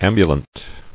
[am·bu·lant]